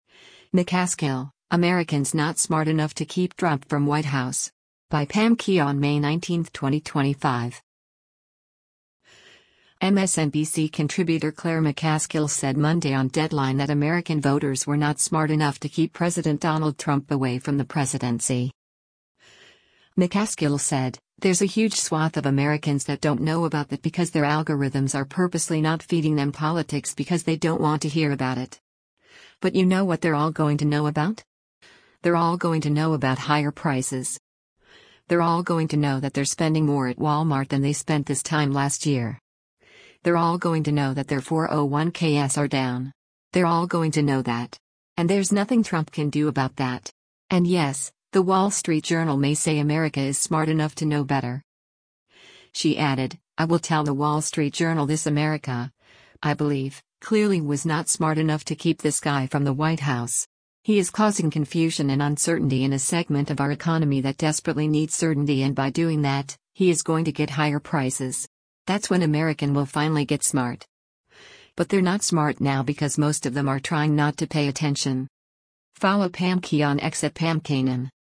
MSNBC contributor Claire McCaskill said Monday on “Deadline” that American voters were “not smart enough” to keep President Donald Trump away from the presidency.